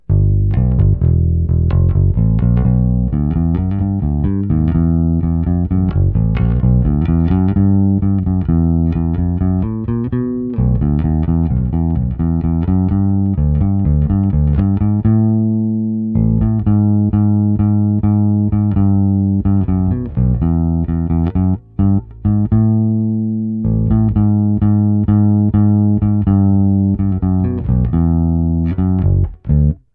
Precision, lehce stažená tónová clona, předzesilovač Fender TBP 1 s korekcemi narovno.
Nahrával jsem to i s puštěným aparátem na "normální" hlasitost, stojící kousek u aparátu, aby byl slyšet rozdíl v otočené fázi.
HPF